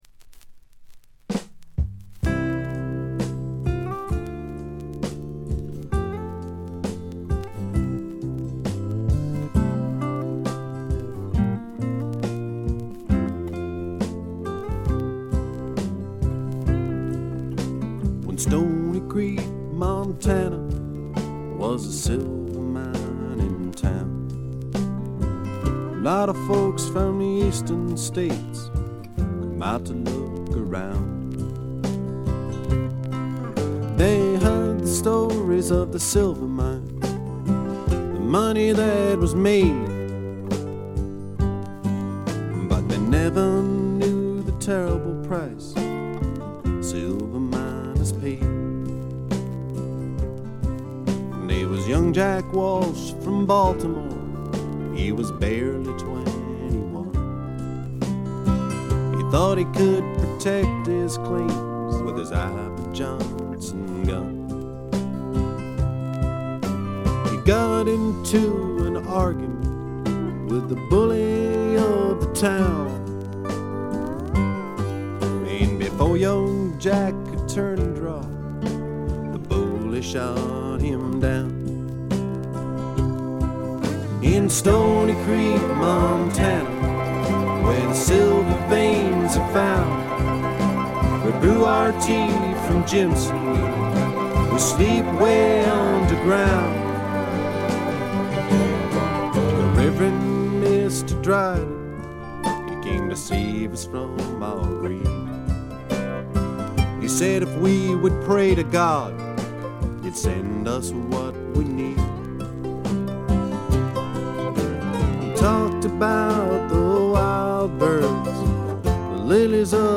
軽微なチリプチが出てはいますが気になるほどのノイズは無いと思います。
試聴曲は現品からの取り込み音源です。
Guitar, Vocals
Drums
Violin, Vocals
Pedal Steel